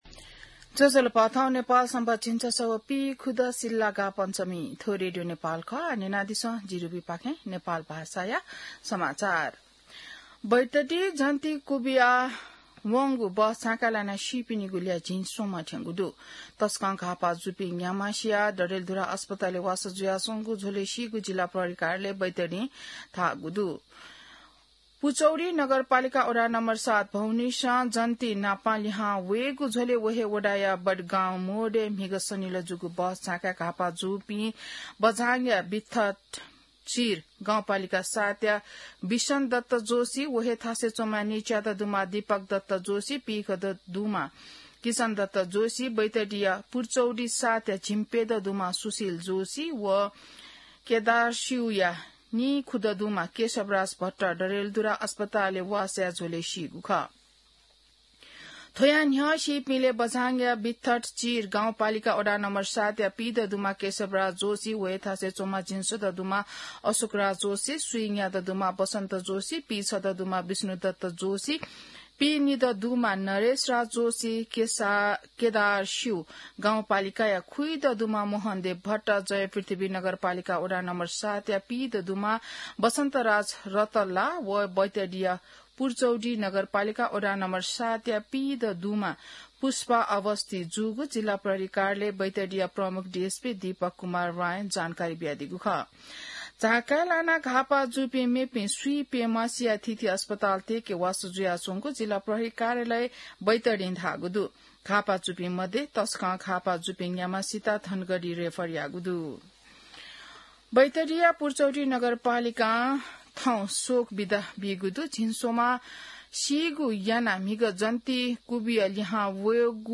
नेपाल भाषामा समाचार : २३ माघ , २०८२